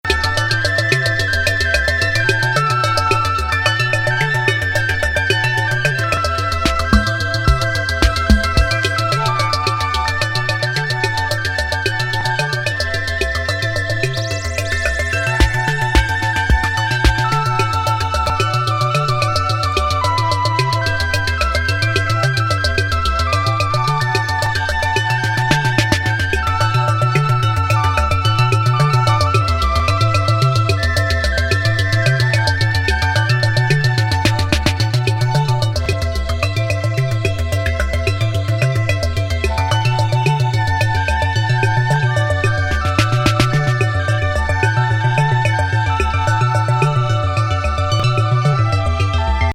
シンセ・シーケンス作。